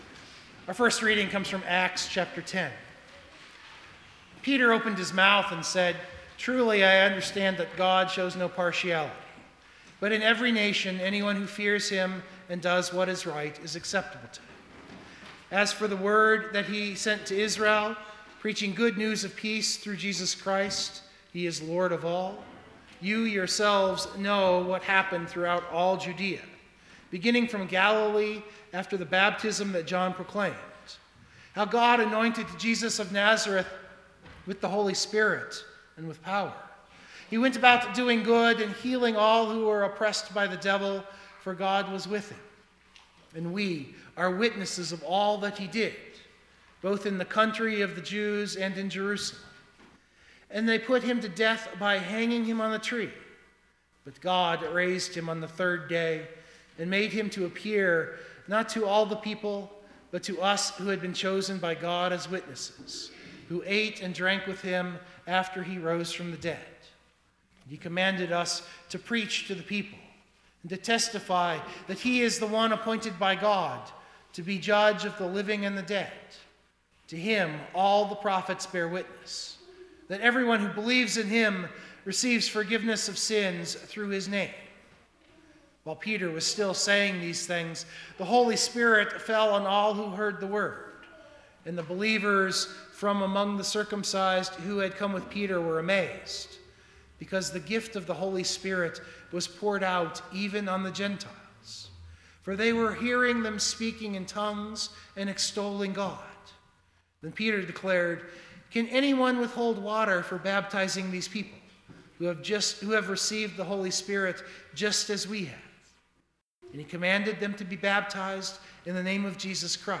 032325 Sermon Download Biblical Text: Luke 13:1-9, Ezekiel 33:7-20 The Gospel Lesson for the day is one of the great teachings of Christianity even though it is not explicitly in the creeds.